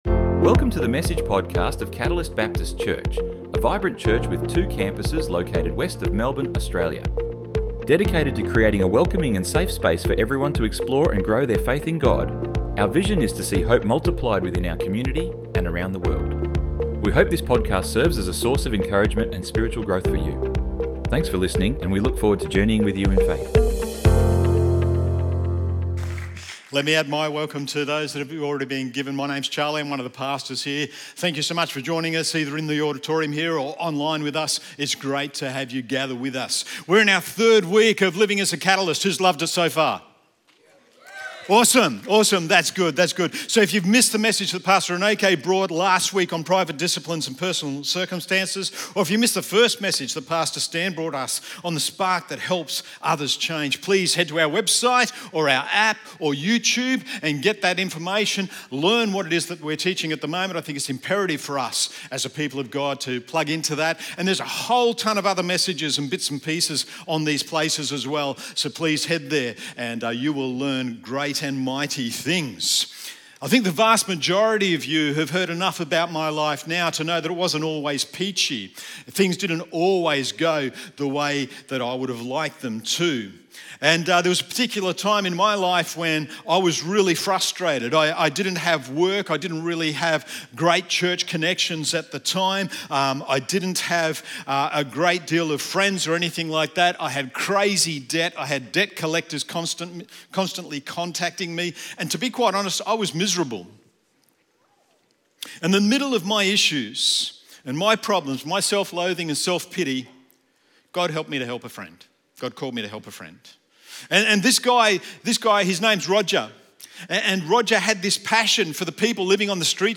Current Sunday Messages